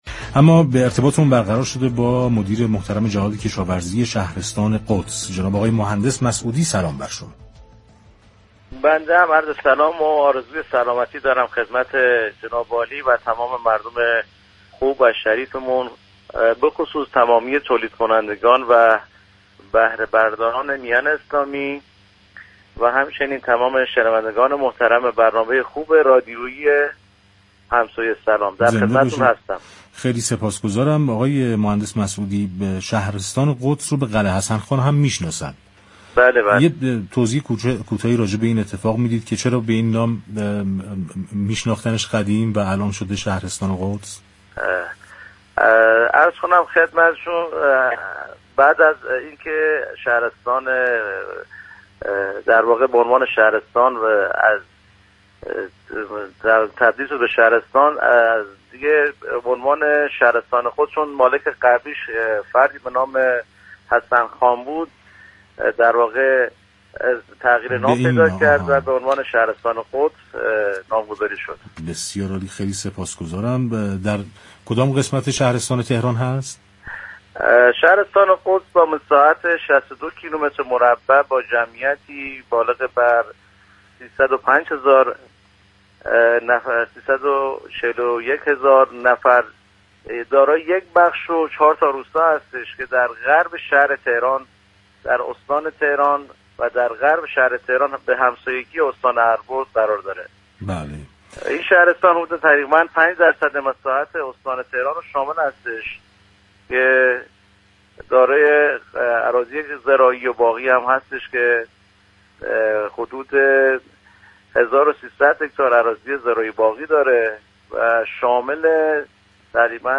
برنامه «همسایه سلام»؛ شنبه تا چهارشنبه ساعت 15 تا 16 از رادیو تهران پخش می‌شود.